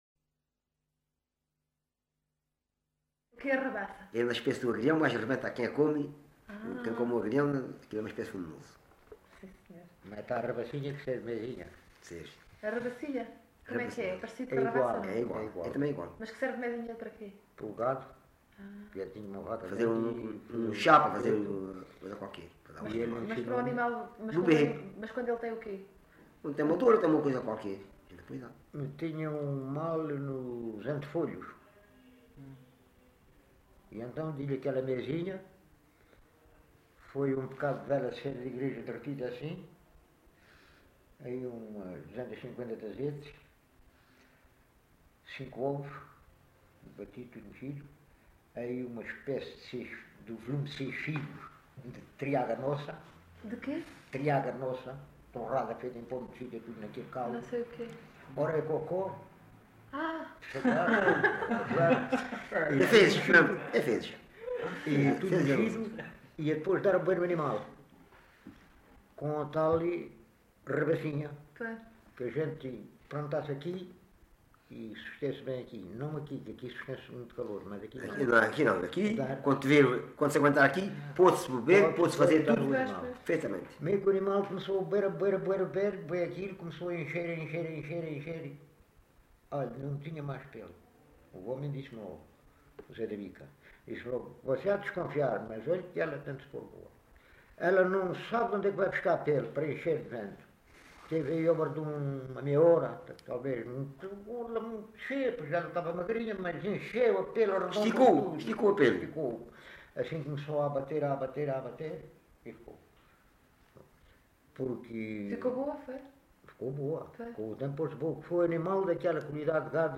LocalidadeAlte (Loulé, Faro)